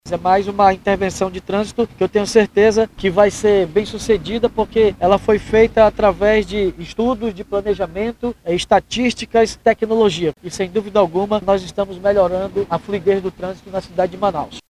Segundo o prefeito David Almeida, essas intervenções são planejadas com base em estudos técnicos para garantir um trânsito mais seguro e eficiente.